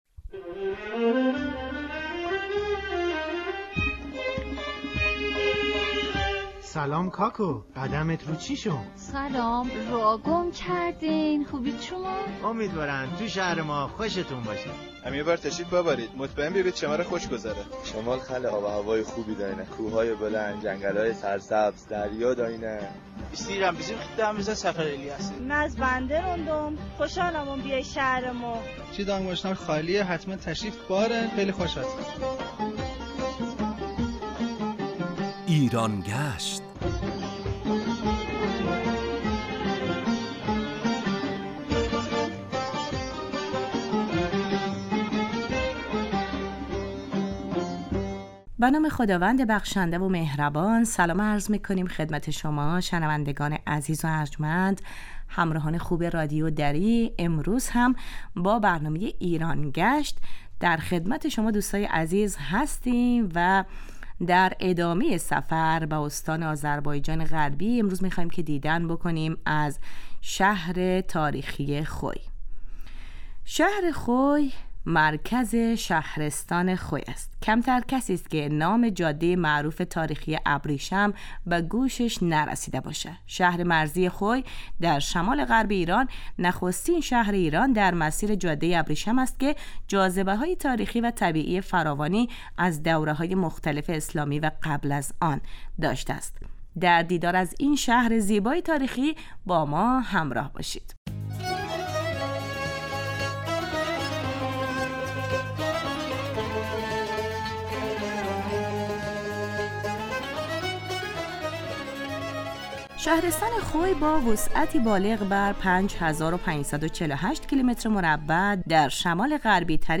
گوینده: سرکار خانم